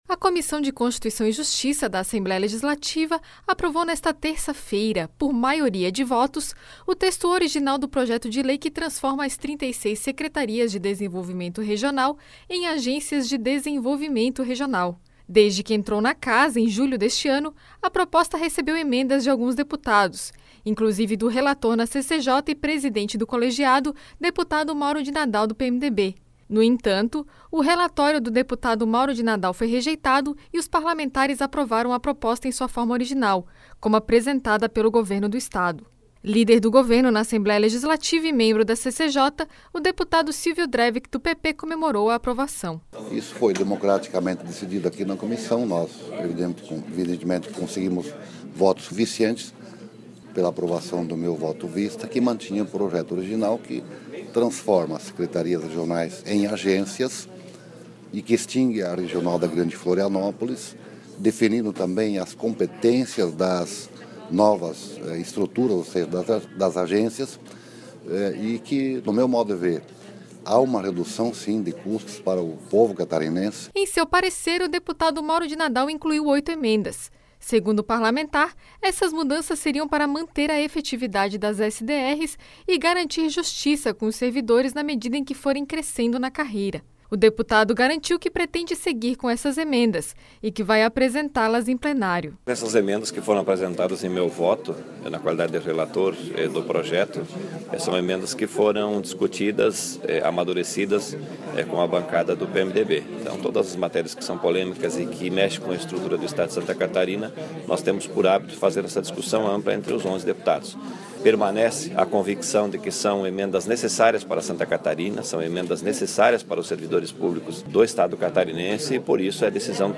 Entrevistas com: deputado Silvio Dreveck (PP), líder de governo na Assembleia Legislativa; deputado Mauro de Nadal (PMDB), presidente da CCJ.